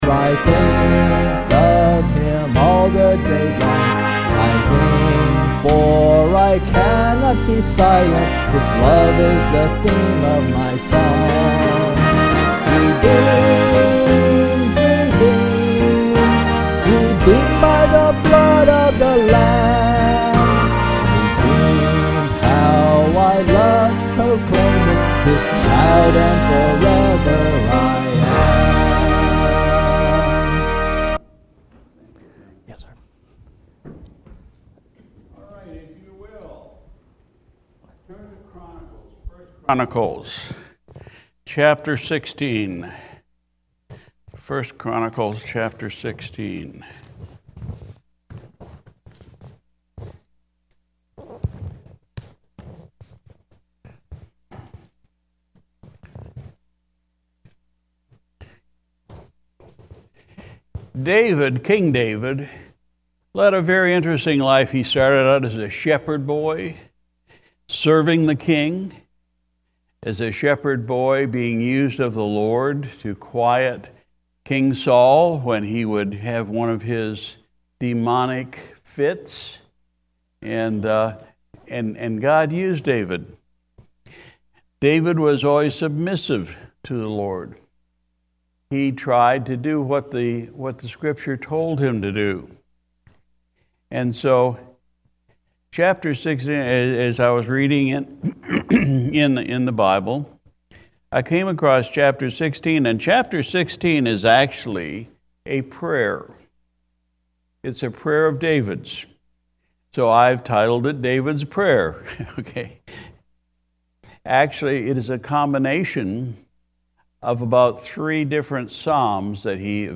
July 10, 2022 Sunday Evening Service Pastor’s Message “David’s Prayer”
Sunday Evening Service